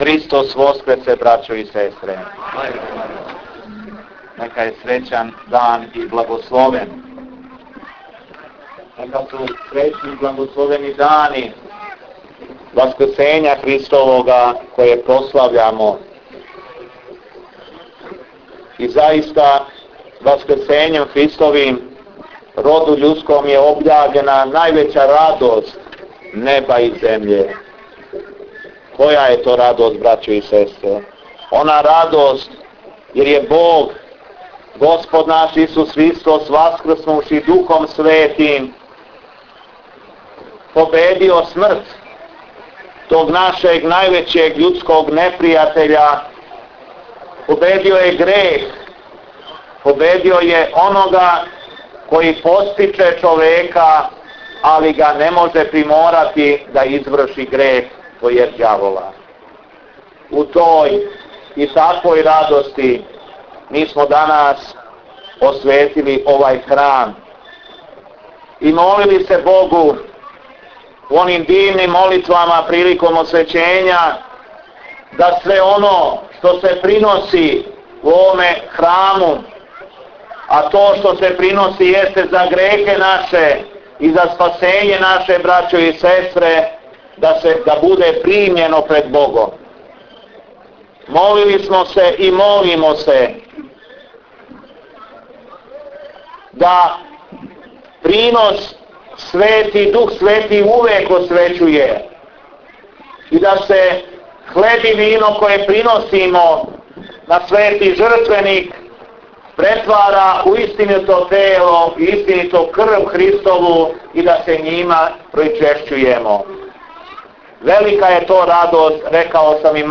БЕСЕДЕ ЕПИСКОПА ЈОВАНА НА ОСВЕЋЕЊУ ЦРКВЕ У КОРИЋАНИМА